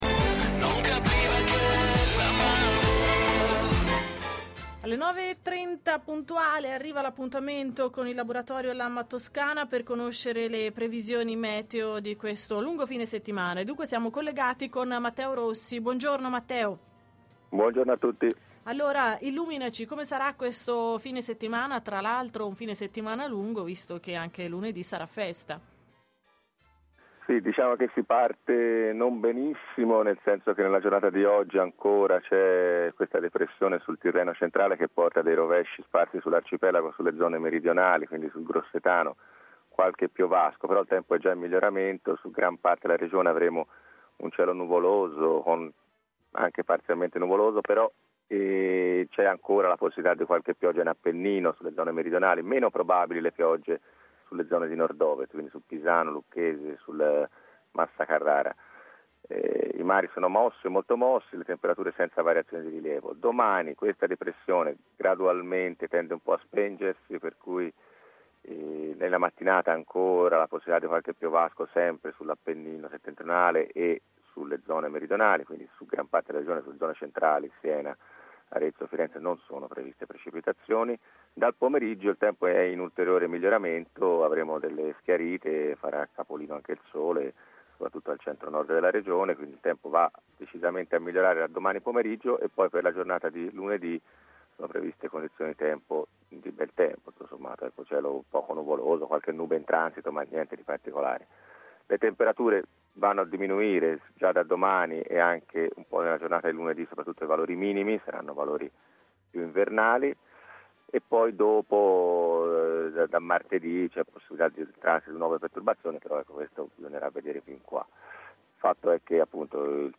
ASCOLTA L’ULTIMO NOTIZIARIO